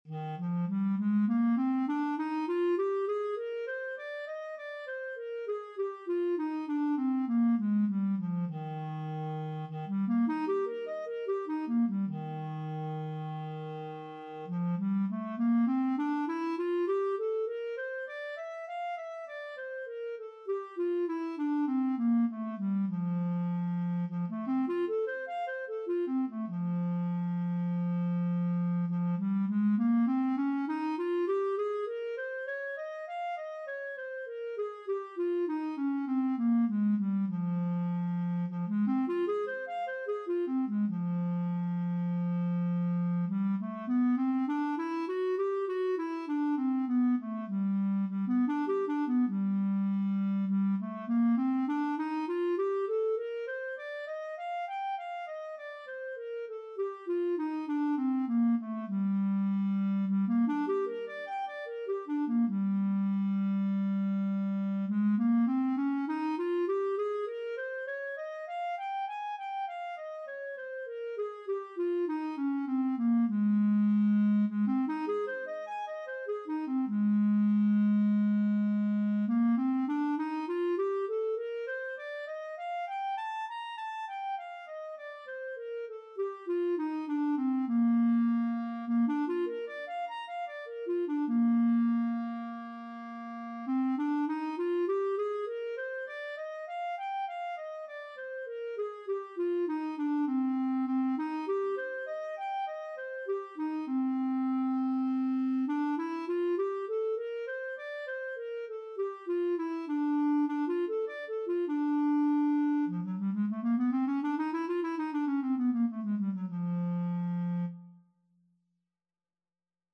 Clarinet scales and arpeggios - Grade 3
Eb major (Sounding Pitch) F major (Clarinet in Bb) (View more Eb major Music for Clarinet )
4/4 (View more 4/4 Music)
Eb4-Bb6
clarinet_scales_grade3_CL.mp3